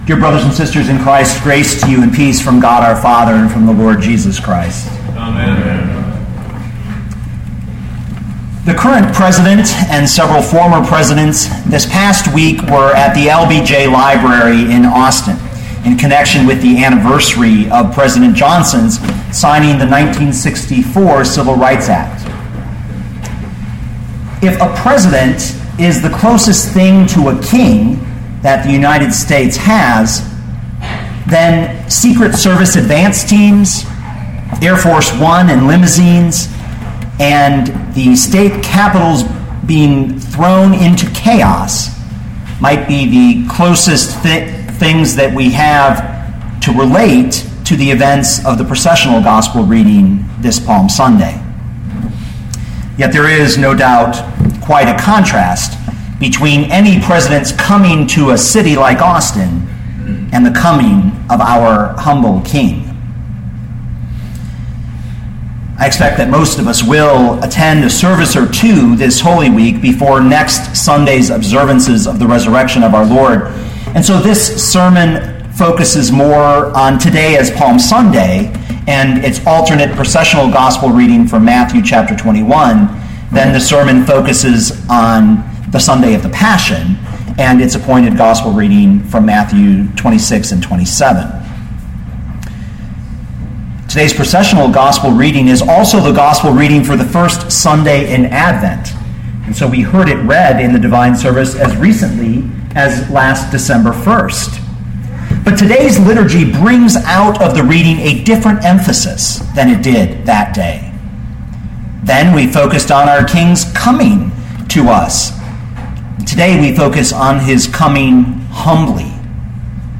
2014 Matthew 21:1-11 Listen to the sermon with the player below, or, download the audio.